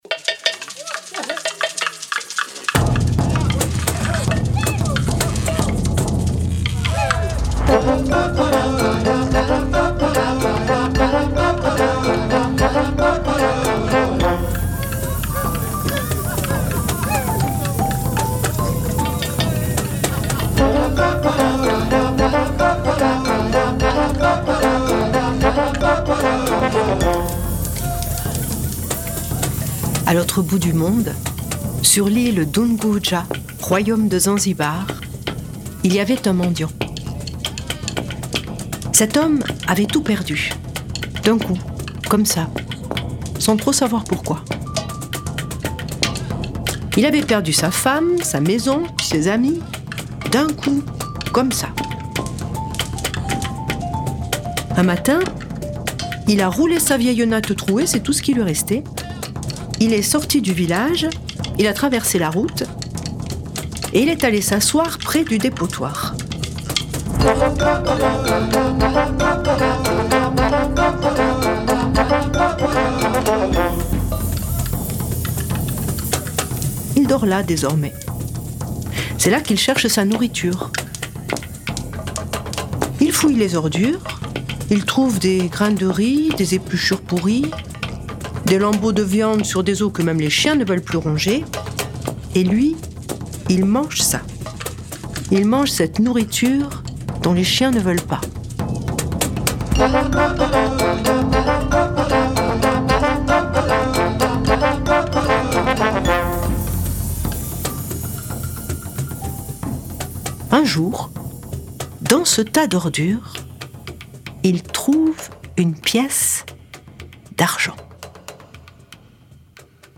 Diffusion distribution ebook et livre audio - Catalogue livres numériques
Dans cette version africaine et musicale du Chat botté, l’antilope fait de son maitre un sultan, mais le roi se souviendra-t-il du mendiant ?